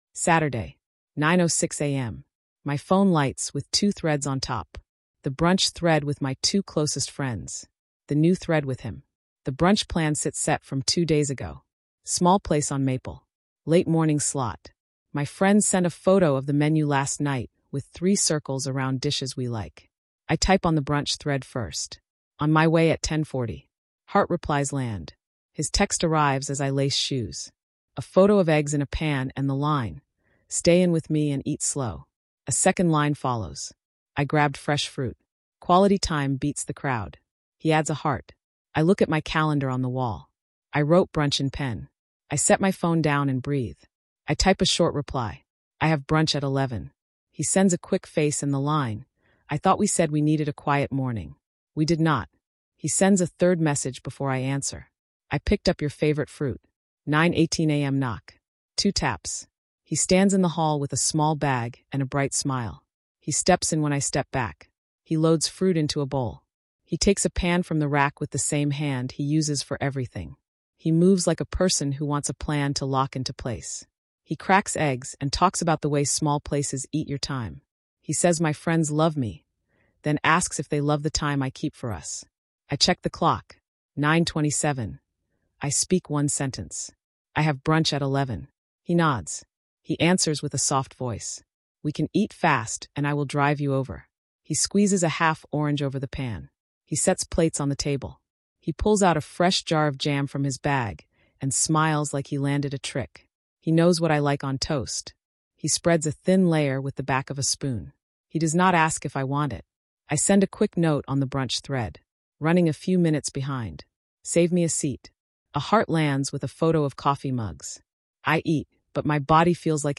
You set rules, confront in public, log times, file a case, and hold a week of silence. This Netflix-style suspense thriller in plain speech tracks manipulation, dark psychology, and social dynamics as pressure shifts to proof.